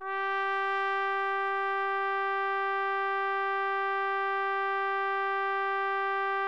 TRUMPET   14.wav